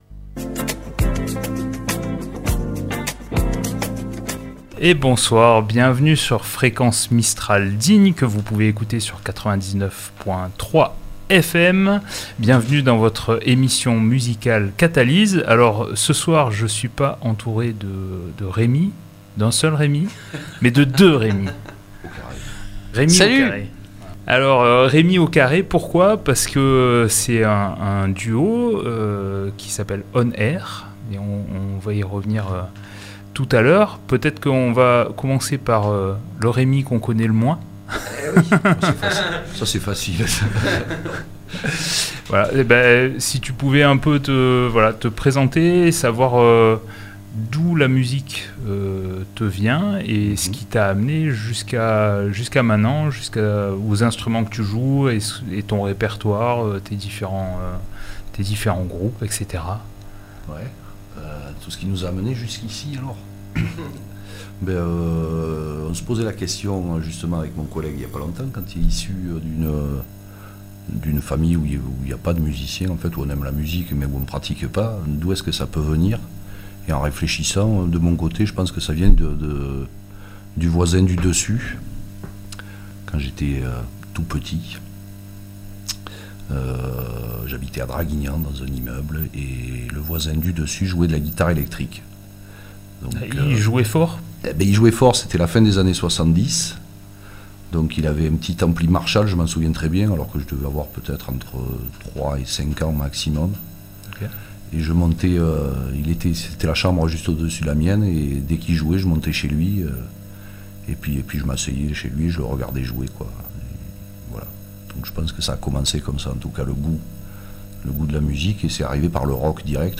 On R Jeudi 28 Mars 2024 Une nouvelle émission musicale pour découvrir les artistes du territoire